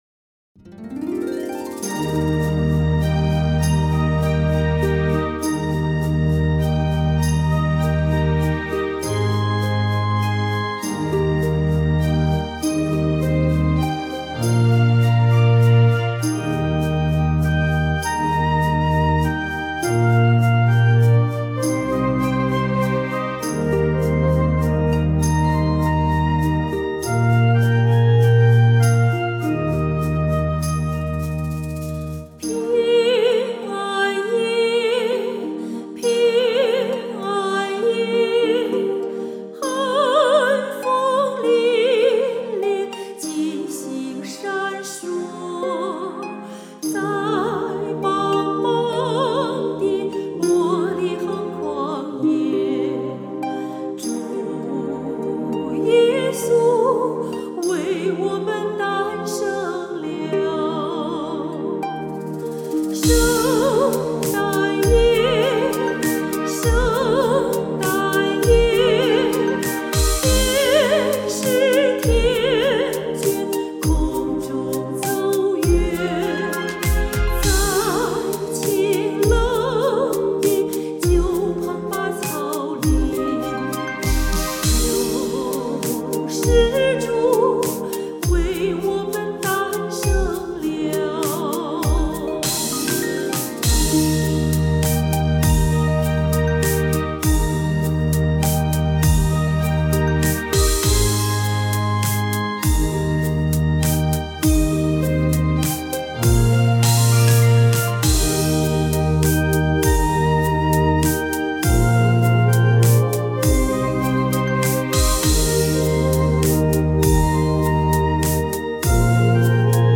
【原创圣歌】
歌词质朴精炼，舍去绮靡，返璞归真，紧合礼仪；结构工整，节奏感强，易于在教会中传唱。整首歌曲将主耶稣降生的环境、主题及意义呈现在字里行间，流露出万民欣喜的愉悦之情；曲调、旋律和谐优美，采用教会传统的礼仪风格，富有庄严、神圣的质感。